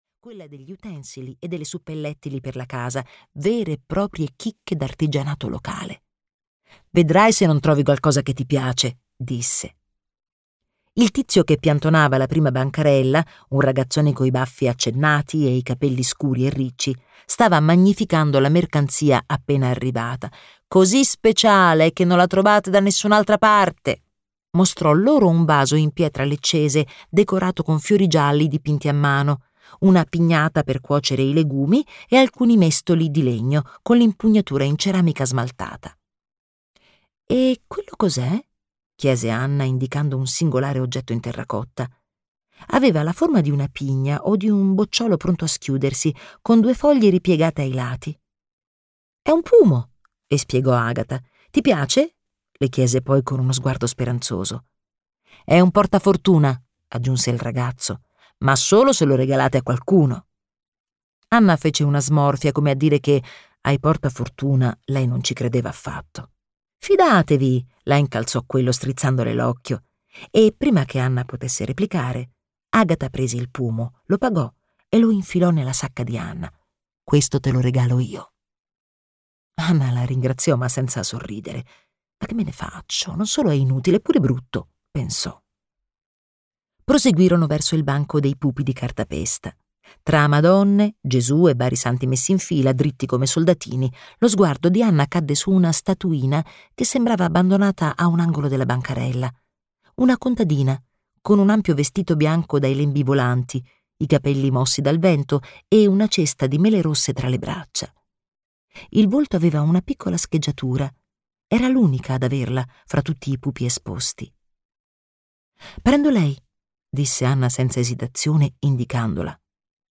"La portalettere" di Francesca Giannone - Audiolibro digitale - AUDIOLIBRI LIQUIDI - Il Libraio